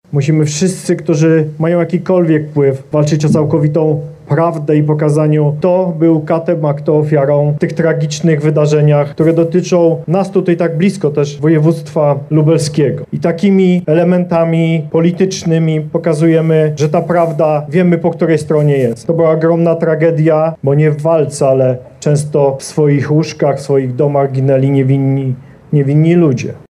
– Nie możemy zapominać o tej ogromnej tragedii – mówił podczas oficjalnych uroczystości wicewojewoda lubelski Andrzej Maj.